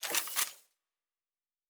pgs/Assets/Audio/Sci-Fi Sounds/Weapons/Weapon 13 Reload 3.wav at master
Weapon 13 Reload 3.wav